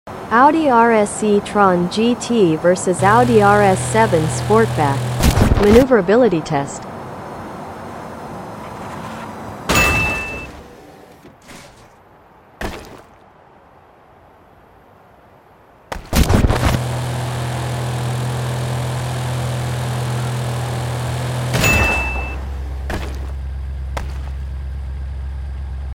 The 2021 Audi RS e tron sound effects free download
The 2021 Audi RS e-tron GT vs. The 2021 Audi RS 7 Sportback Part 9 in Forza Horizon 5 Using Xbox Wireless Controller Gameplay.